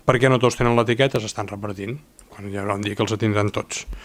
L’alcalde Buch li responia així: